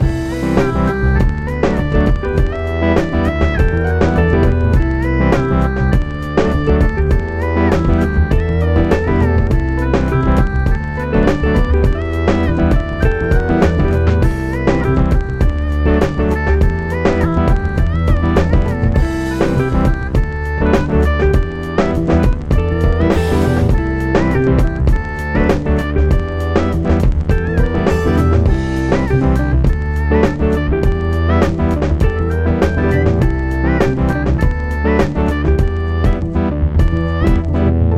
Recorded on Yamaha Motif 8, Intro and loop.